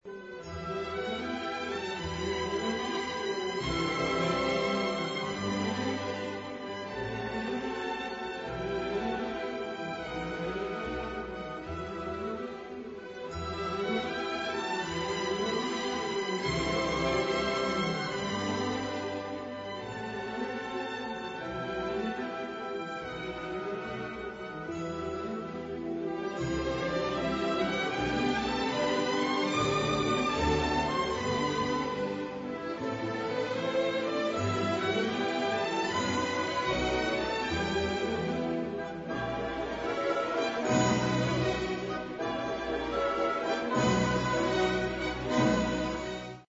Vltava (MP3) (symfonická báseň z cyklu Má vlast, hraje Česká filharmonie, dir. Rafael Kubelík, Supraphon 1990)